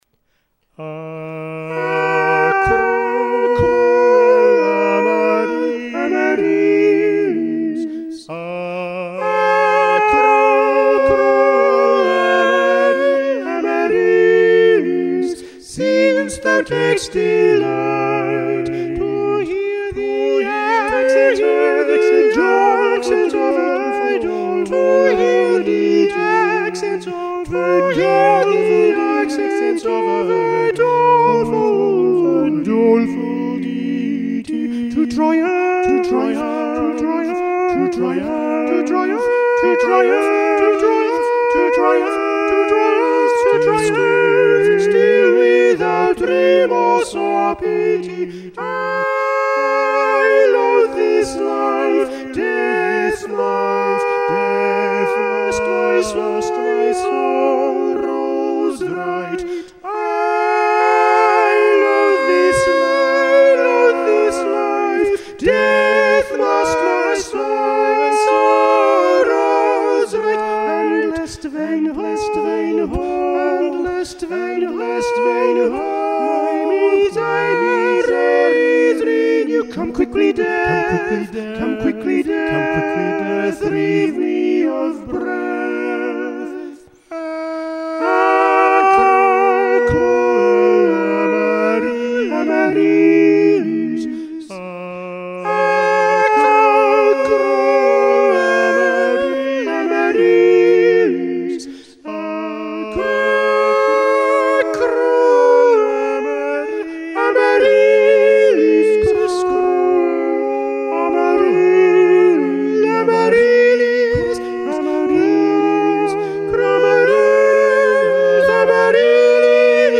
Madrigals for Three Voices